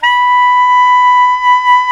Index of /90_sSampleCDs/Roland LCDP07 Super Sax/SAX_Tenor V-sw/SAX_Tenor _ 2way
SAX TENORB0Z.wav